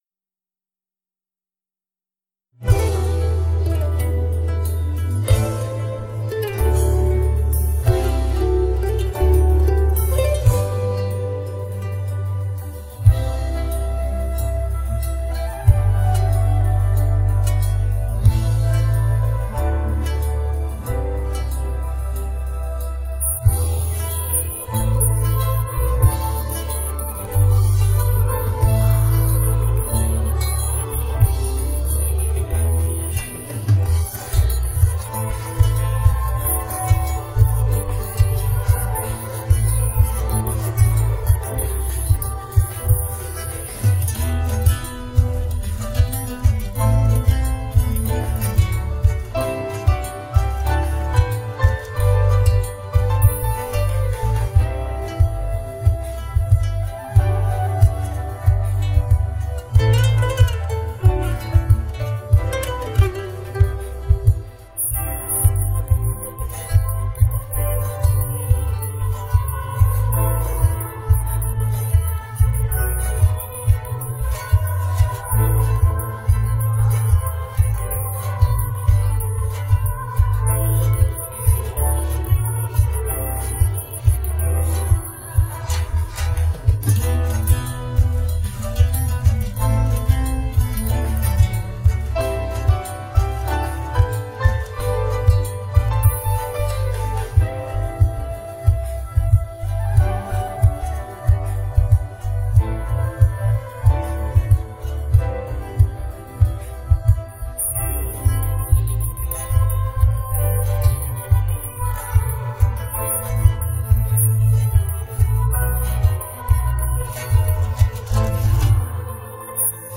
Sing with Us
Play Karaoke and Sing with Us